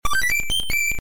KIrby 1up Sound Effect Owend Sound Effects Free Download